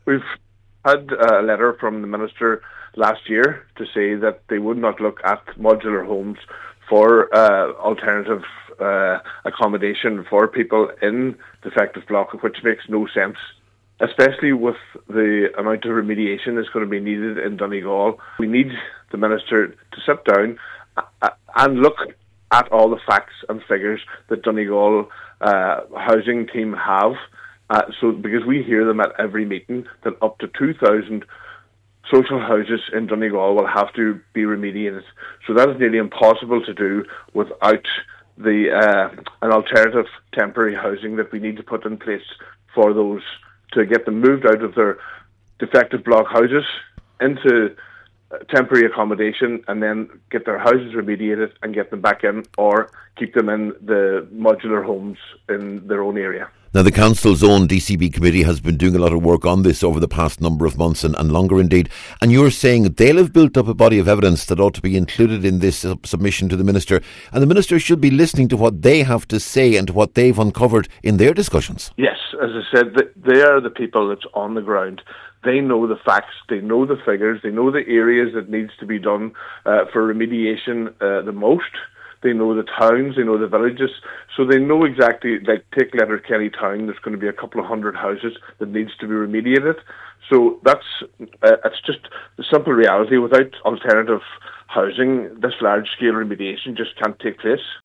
Speaking to Highland Radio News ahead of the meeting, Cllr Carr added that the request should be supported by data from the Council and the Defective Concrete Blocks Committee: